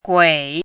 怎么读
guǐ
gui3.mp3